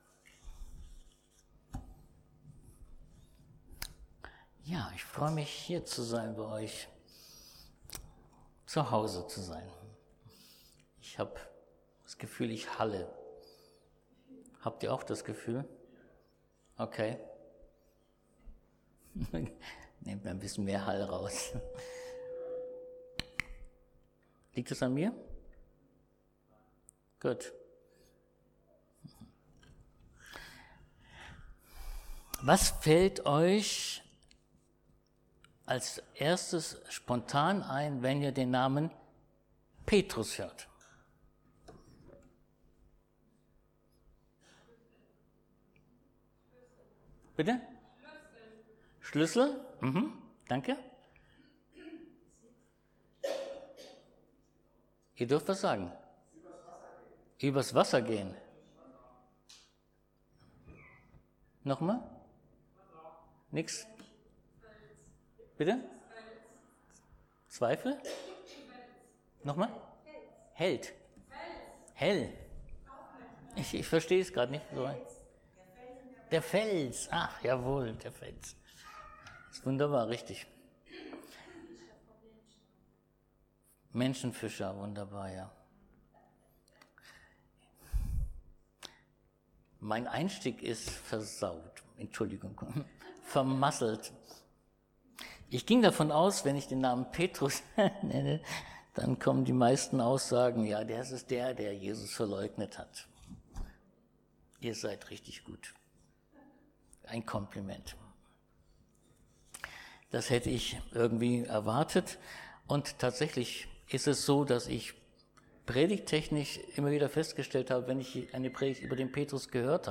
Lukas 22. 31-32 Dienstart: Predigt Ein Vorbild für uns.